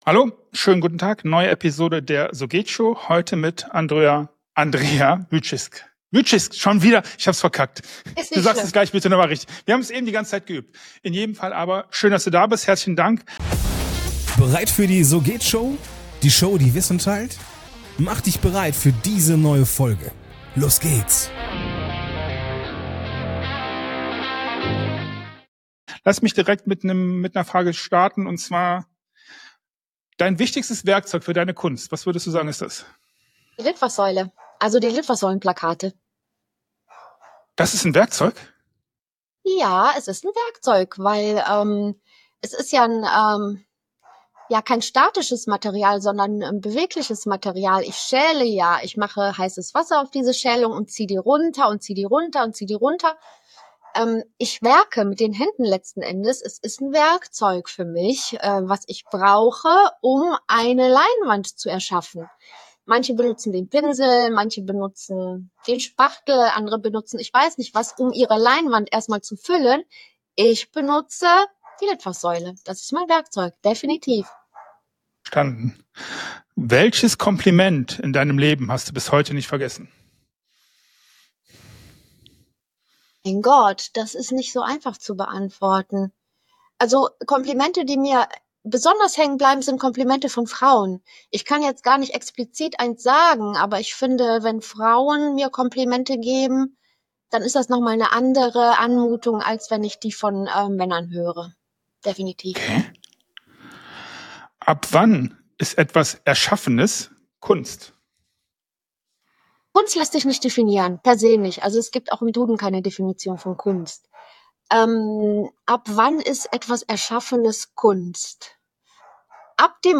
Ein Gespräch über Inspiration, künstlerische Disziplin, Business-Realität und mutige Visionen.